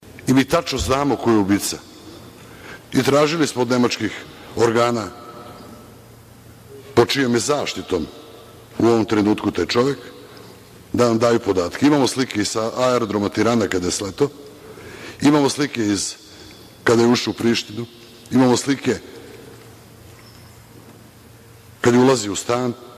Gašić na sednici Skupštine 21. juna o navodnom počiniocu ubistva Olivera Ivanovića